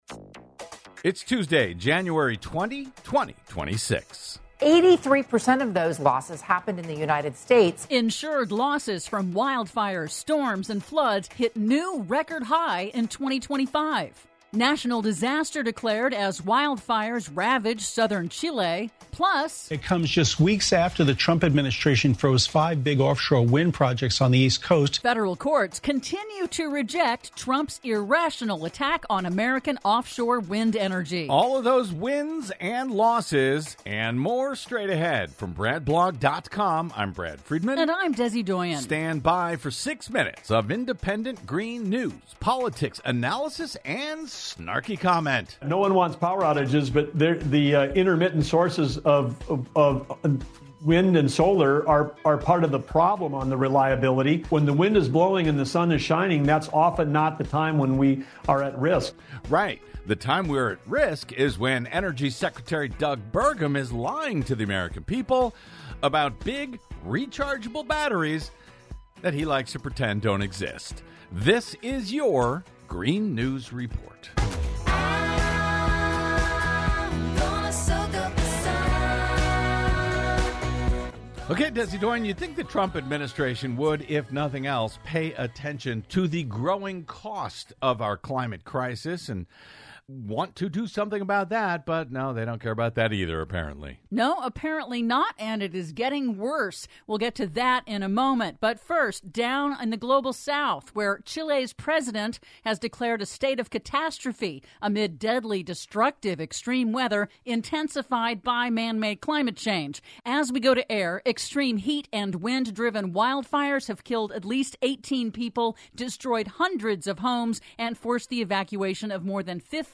IN TODAY'S RADIO REPORT: Insured losses from wildfires, storms and floods hit new record high in 2025; National disaster declared as wildfires ravage southern Chile; Torrential rains and deadly floods inundate parts of South Africa; PLUS: Federal courts continue to reject Trump's irrational attack on American offshore wind energy... All that and more in today's Green News Report!